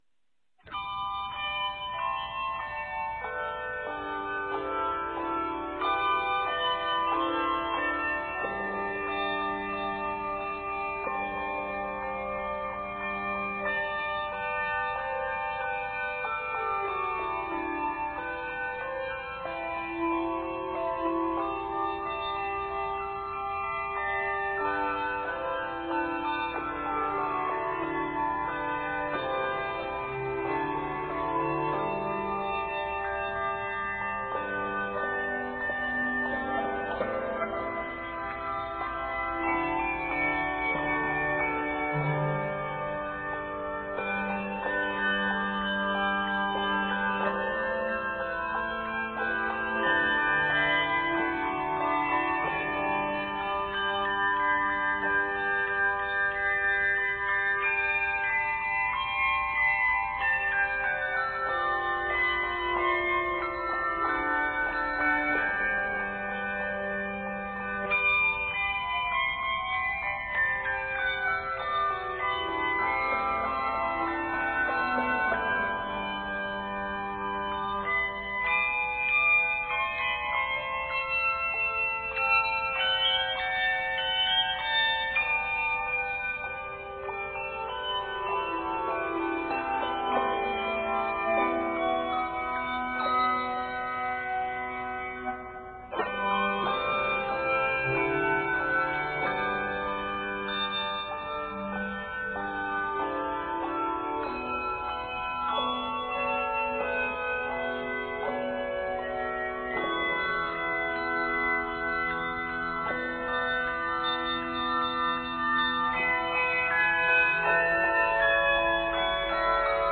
The last section is broader and in a new key.
Set in G Major and E Major, this piece is 65 measures.
Octaves: 3-5